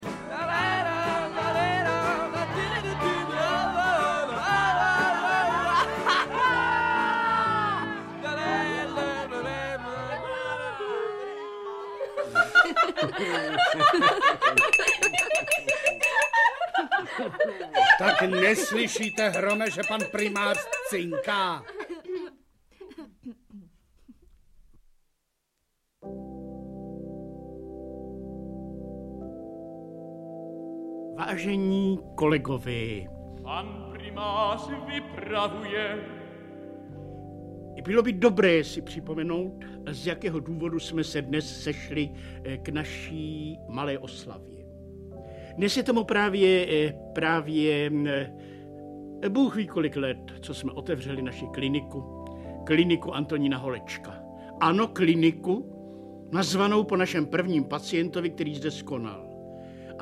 Audiobook
Read: Jitka Molavcová